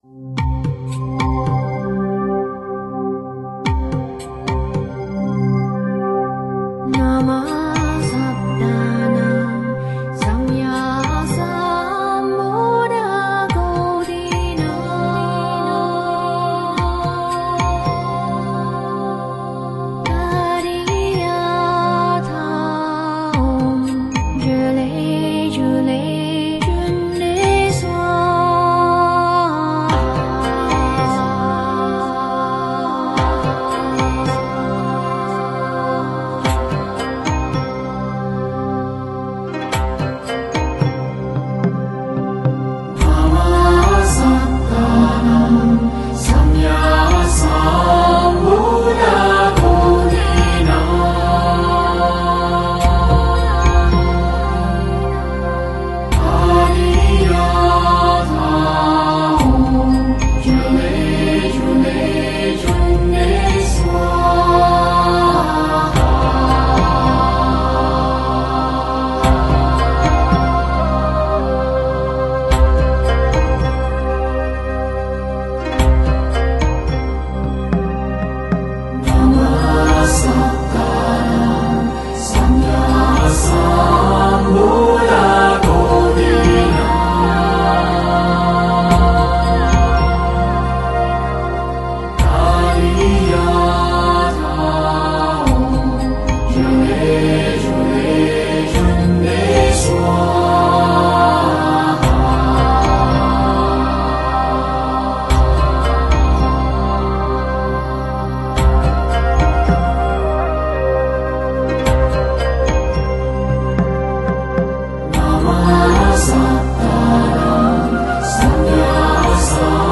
» Thanh Tịnh Pháp Âm » Mật Chú Tiếng Phạn » Cundī Thần Chú  cundi dhāraṇī namaḥ saptānāṃ samyak-saṃbuddha-koṭīnāṃ tadyathā oṃ cale cule cunde svāhā ► ♫ Cundi Spirit Heart Mantra ► ♫ Cundi Spirit Mantra
Cundi Spirit Mantra.ogg